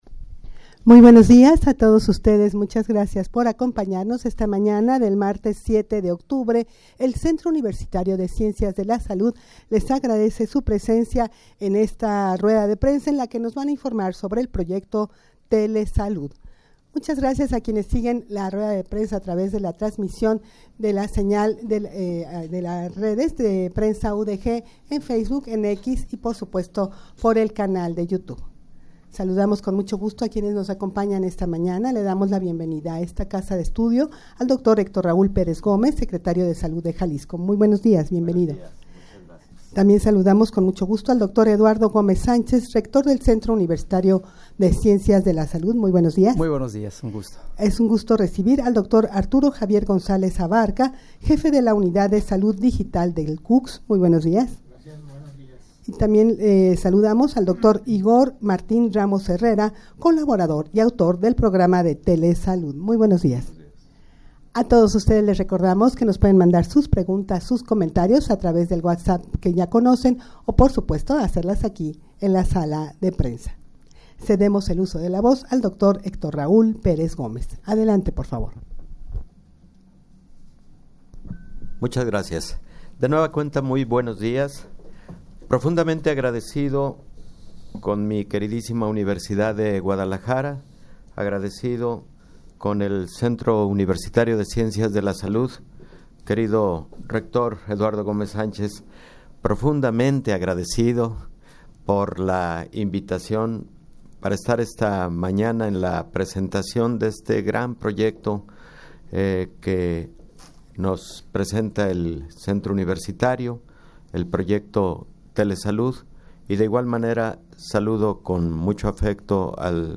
Audio de la Rueda de Prensa
rueda-de-prensa-para-informar-del-proyecto-tele-salud.mp3